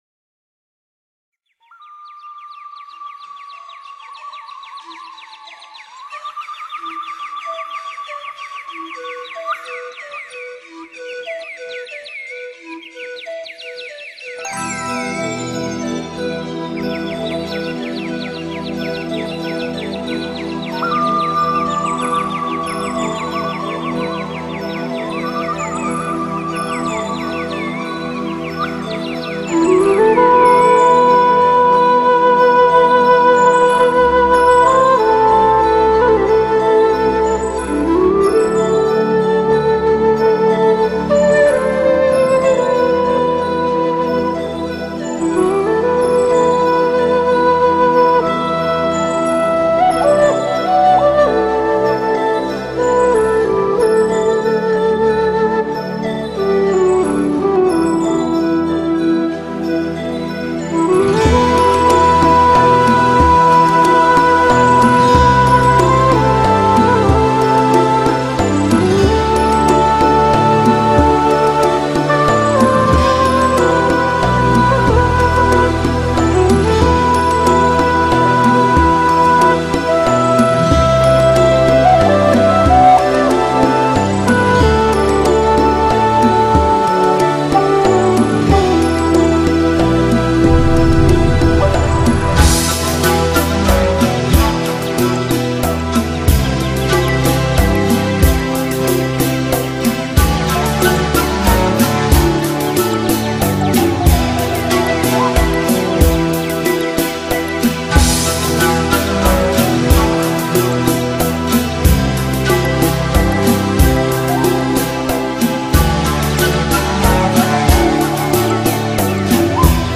Native American Indian Spiritual Music - Ce (1).mp3